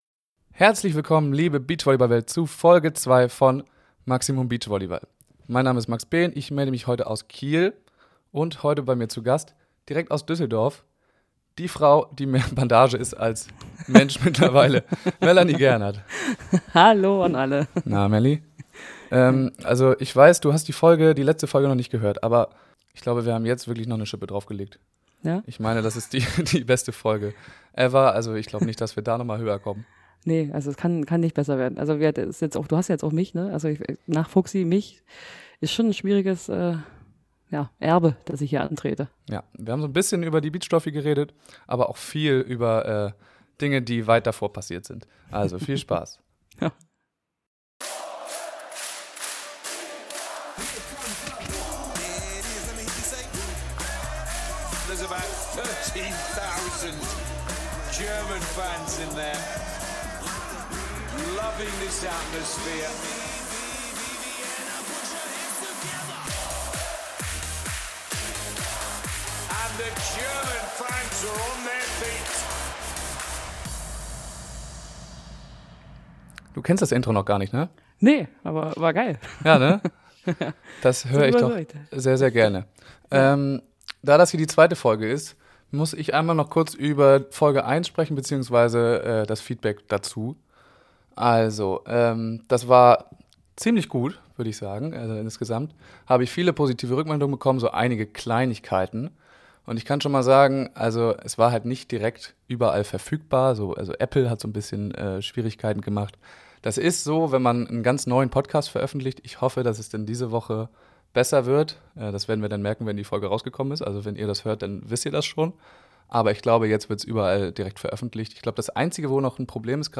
Die Aufnahmesituation stellte uns vor einige Schwierigkeiten, Entschuldigt bitte etwaige Hintergrundgeräusche und Verbindungsprobleme!